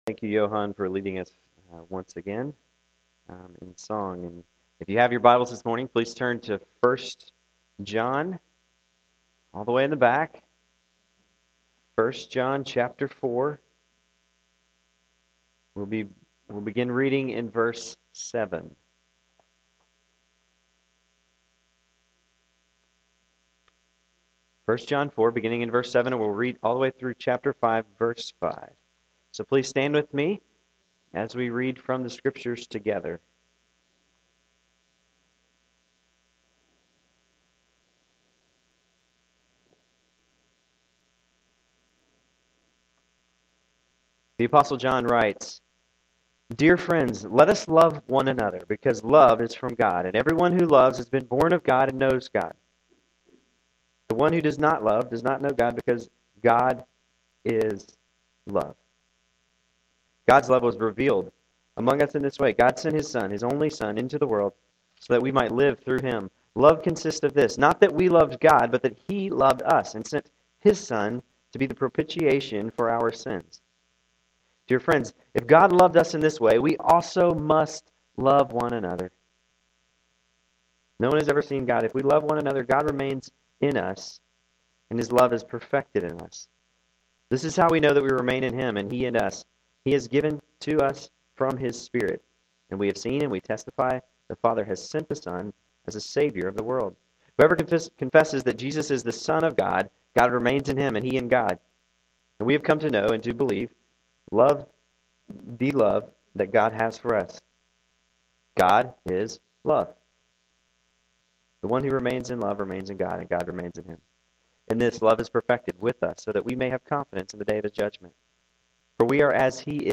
A Spring coolness returned to Louisville, but that didn’t keep a number of members and guests from gathering to worship the LORD this morning.